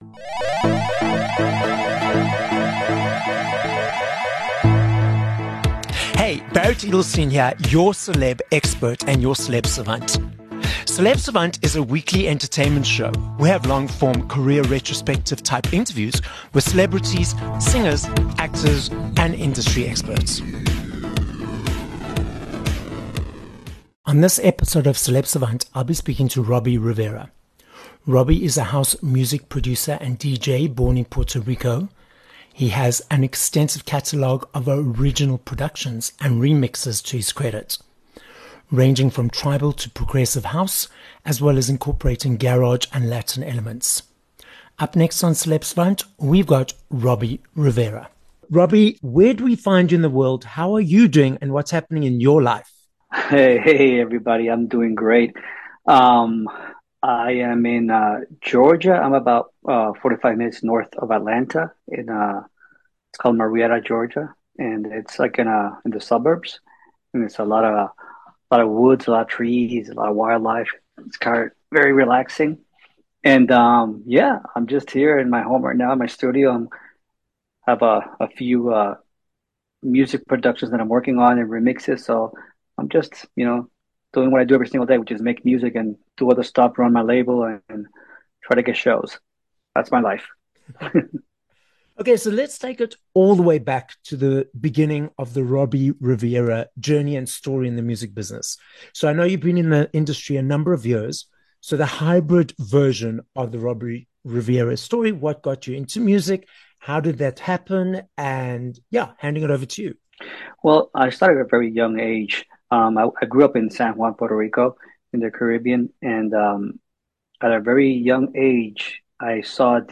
23 Jan Interview with Robbie Rivera